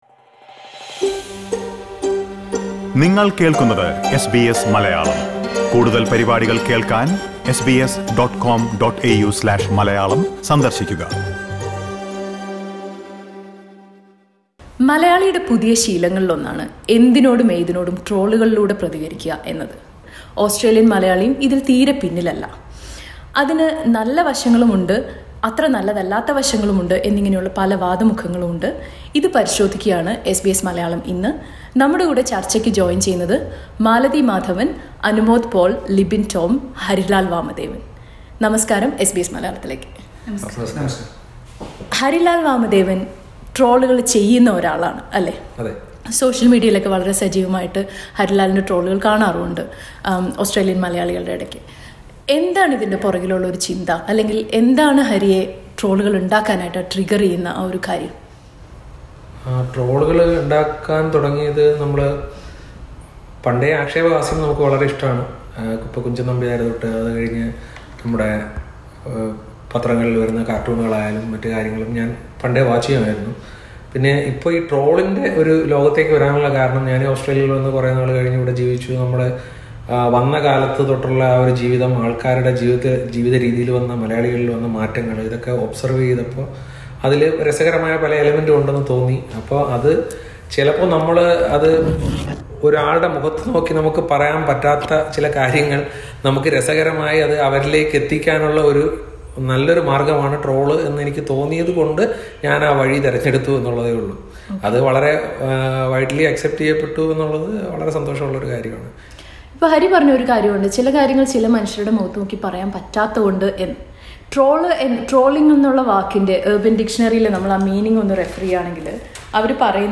ഇതേക്കുറിച്ച് ഓസ്‌ട്രേലിയന്‍ മലയാളികളുമായി നടത്തിയ ഒരു ചര്‍ച്ച കേള്‍ക്കാം, മുകളിലേ പ്ലേയറില്‍ നിന്ന്...